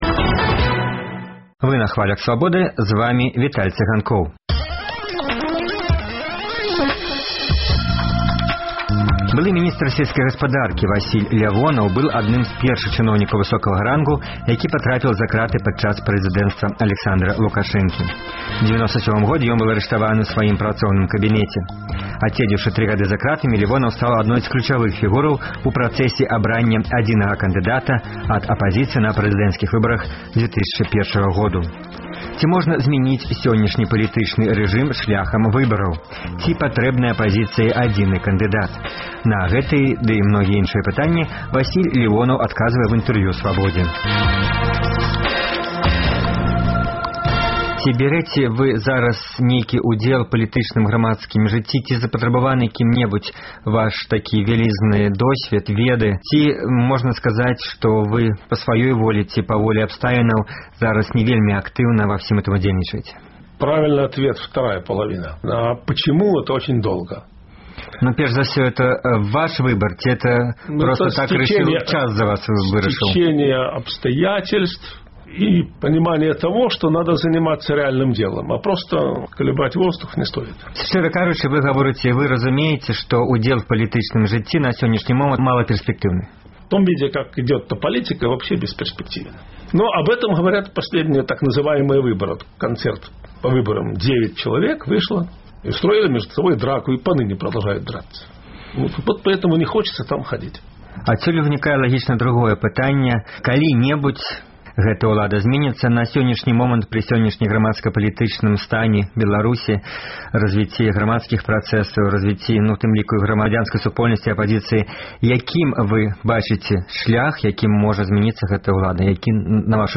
Онлайн-канфэрэнцыя
На пытаньні Свабоды адказвае былы міністар сельскай гаспадаркі Васіль Лявонаў.